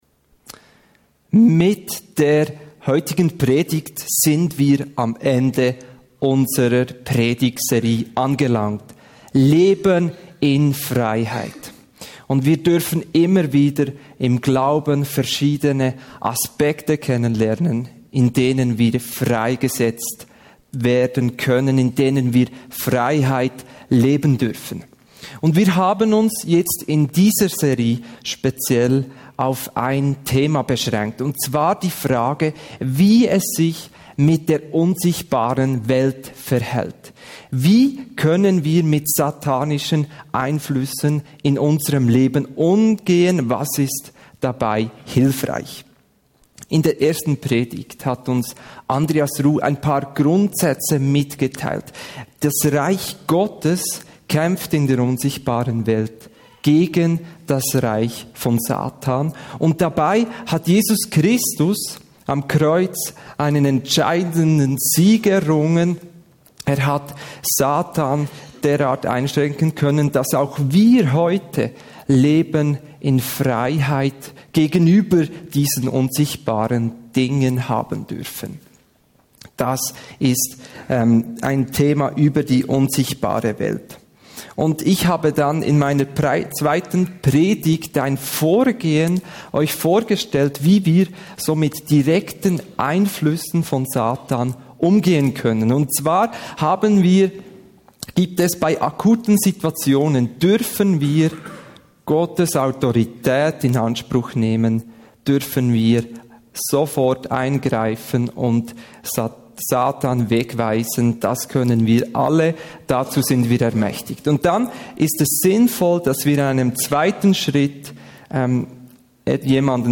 191208_predigt.mp3